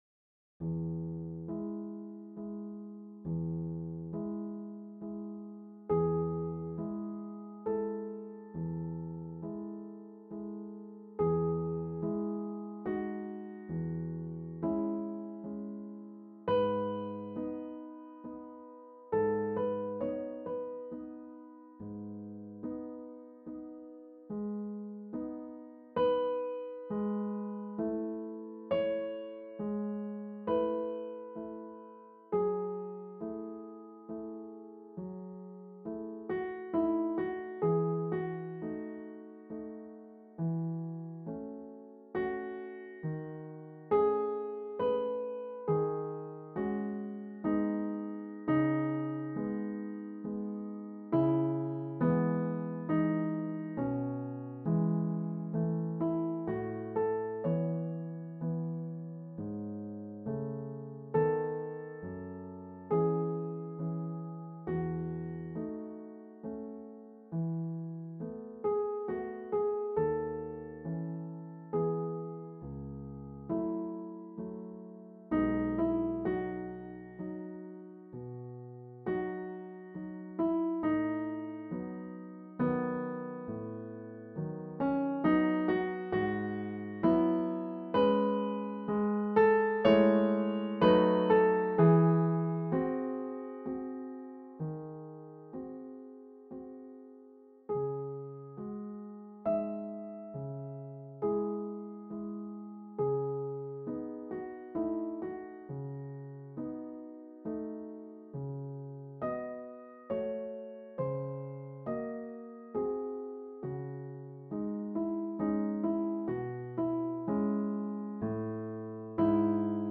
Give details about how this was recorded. As odd as this may sound, I didn't actually play any of these pieces on a piano. The performances were put together on a computer, where my job was to decide for each note just when it should hit, how hard, and for how long.2